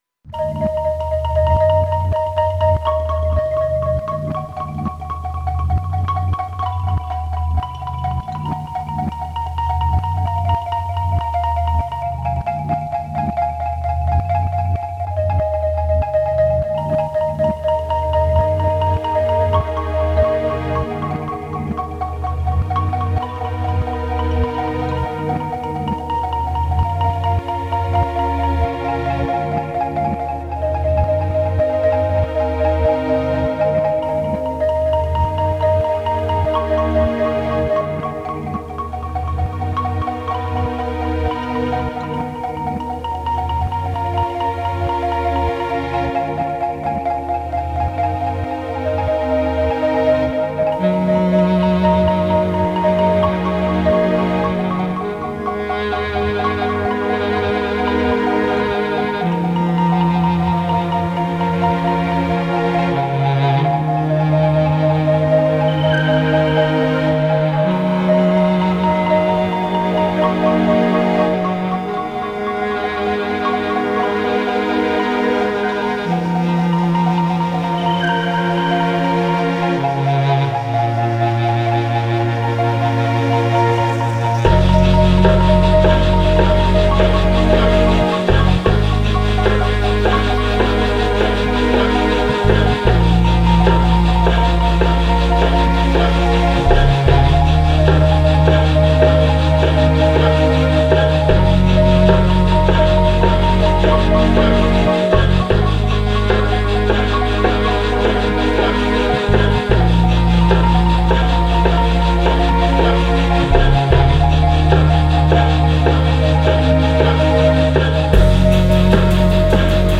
A wonderful timeless release with a cinematic twist.
Timeless cinematic electronic music.
cinematic downtempo